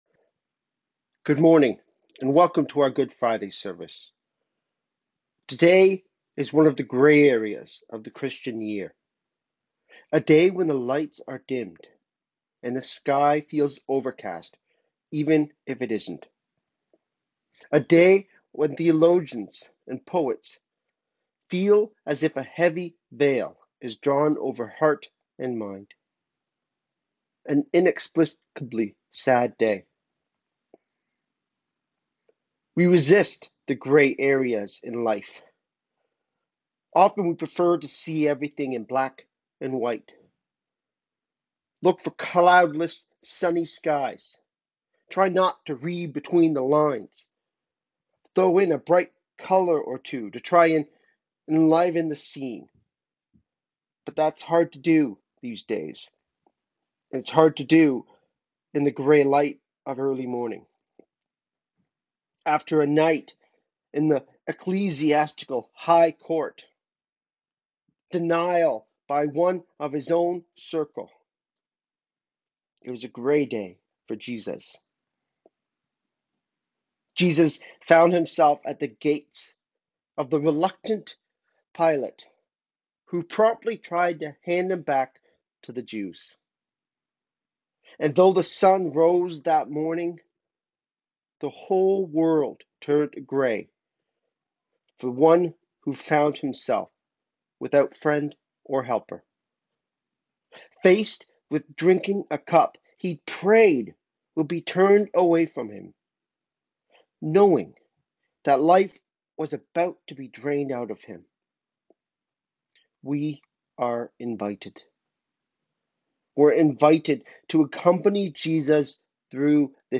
Good Friday Service
We will be partaking of the Lord’s Supper together during this service. I’ve left a pause in the service for you to get the elements together but feel free to gather them before you being the recording. As I mention in the recording, you are welcome to use bread and wine but can also use any food and drink that you have in your home.